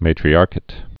(mātrē-ärkĭt, -kāt)